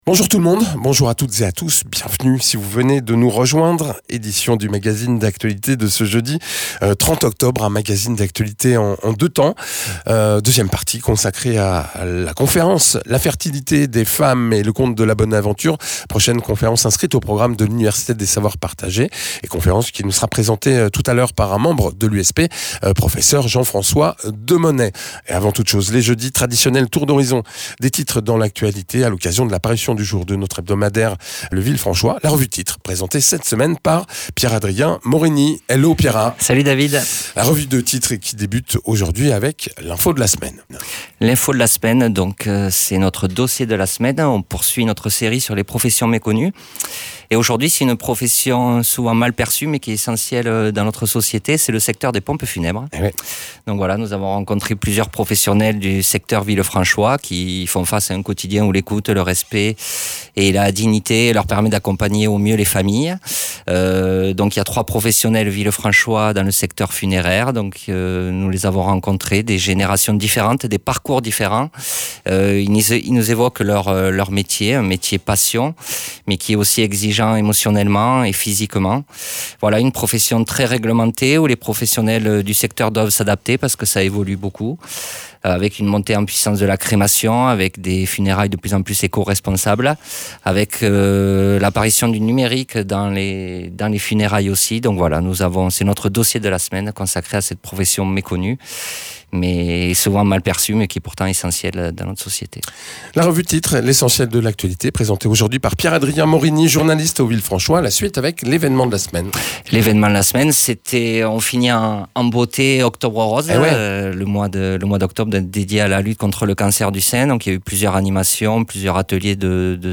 Dans la revue de presse, on parle des métiers atypiques et mal connus du secteur funéraire, du succès de la campagne Octobre Rose pour soutenir la lutte contre le cancer et de toute l’actualité sportive. La 2ème partie est consacrée à la prochaine conférence de l’Université des Savoirs Partagés à propos de la fertilité.